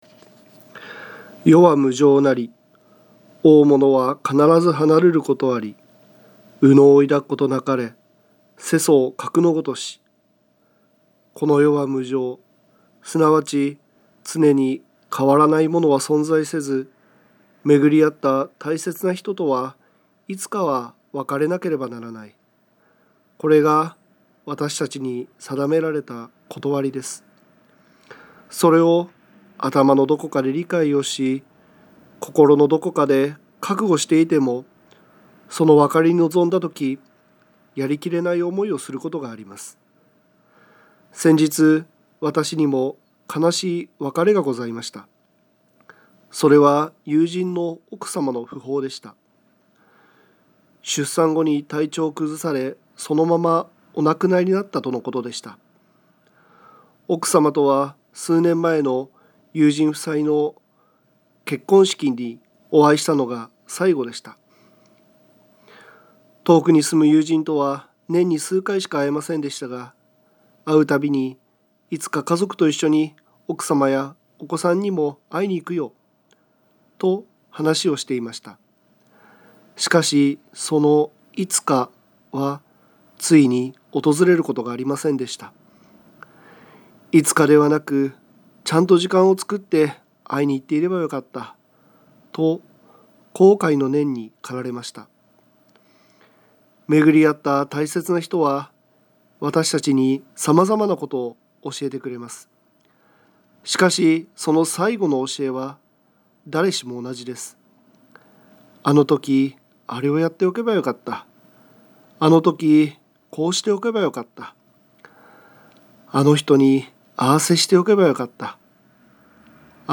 曹洞宗岐阜県宗務所 > テレフォン法話 > 「世は無常なり」